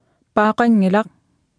Speech synthesis Martha to computer or mobile phone